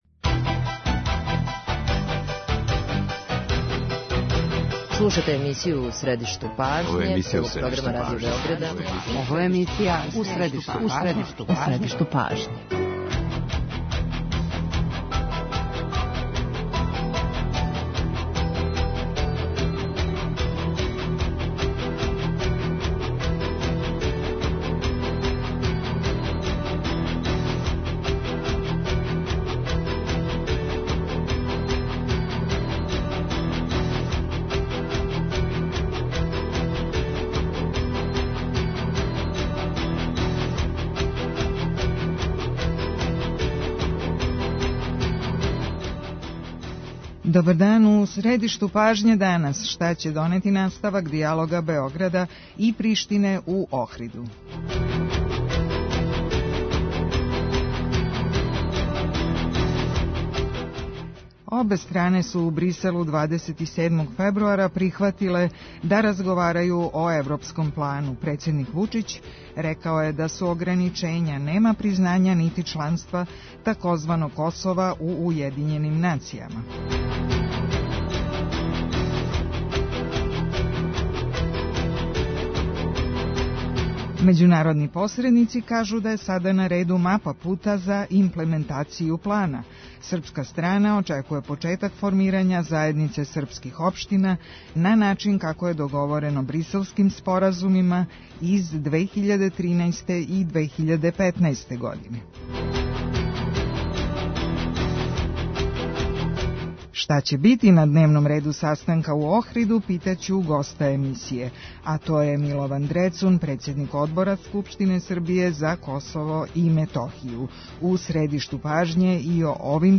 Гост емисије је Милован Дрецун, председник Одбора Скупштине Србије за Косово и Метохију.